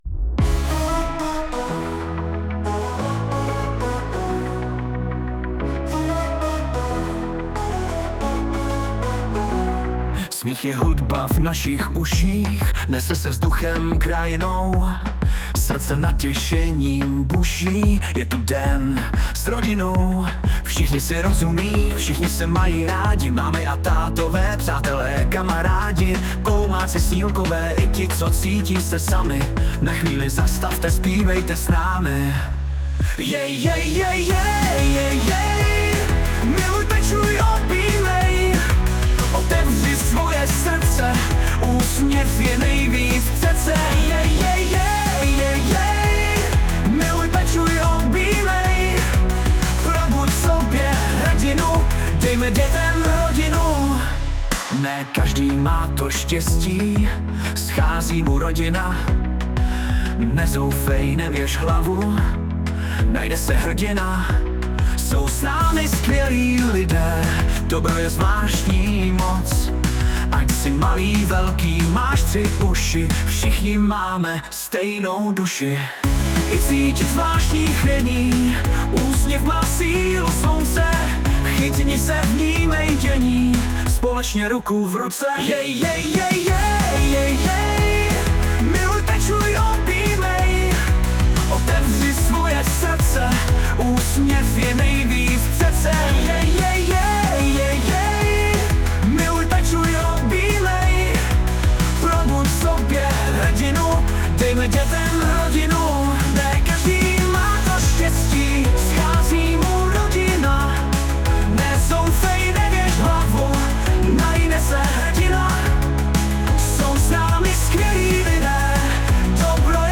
Znělka